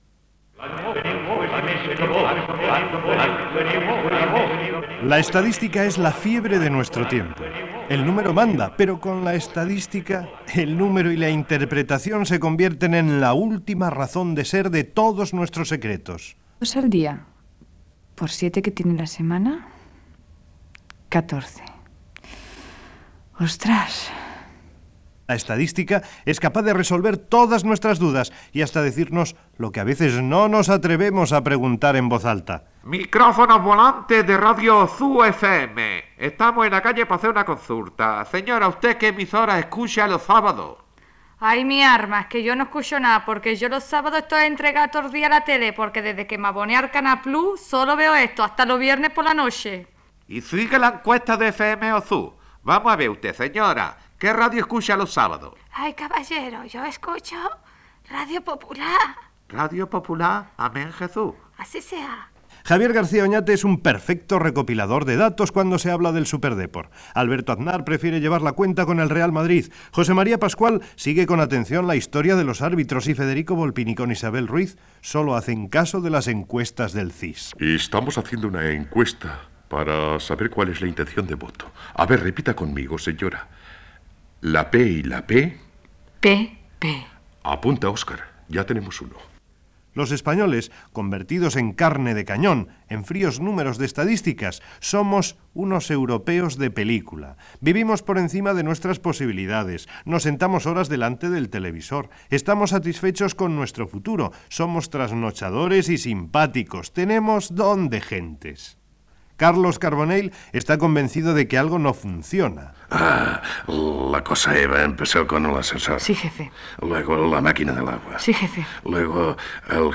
Comentari humorístic sobre l'estadística i els nombres amb esment a diversos integrants de l'equip
Entreteniment